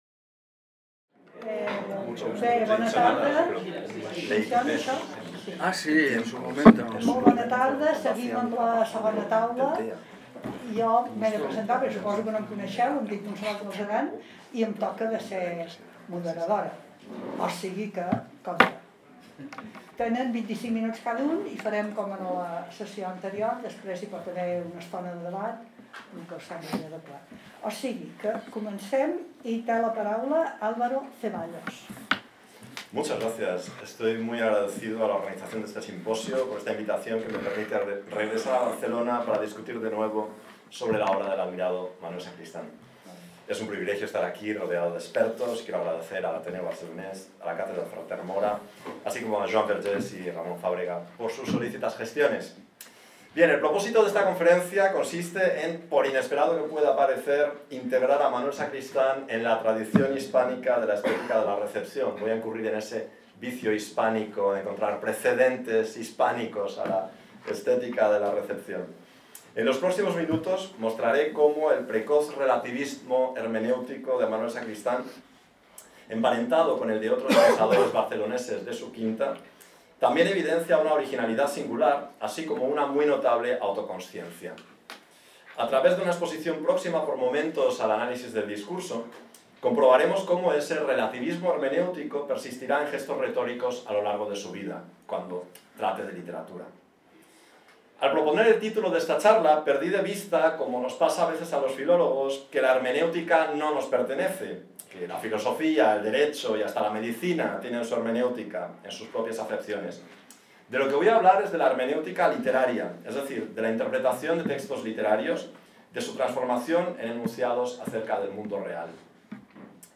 >  Aquesta conferència s'emmarca dins el Simposi Trias 2025 dedicat al filòsof Manuel Sacristán. Organitzat per la Càtedra Ferrater Mora i realizat a l'Ateneu Barcelonès.